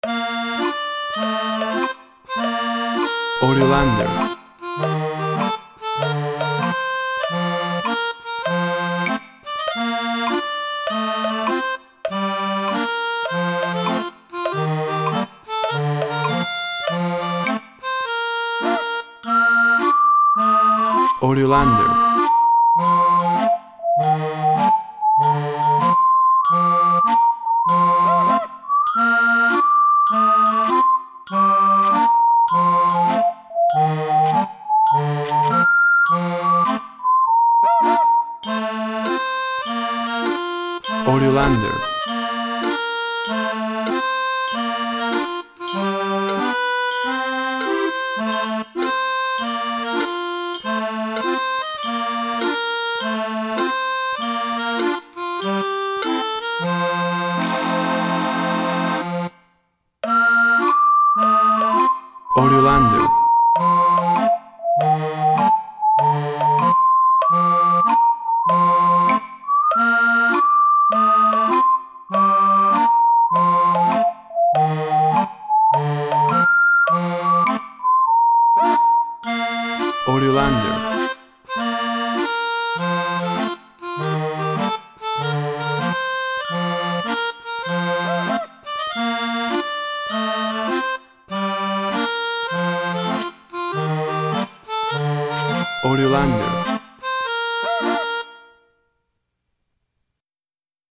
WAV Sample Rate 24-Bit Stereo, 44.1 kHz
Tempo (BPM) 98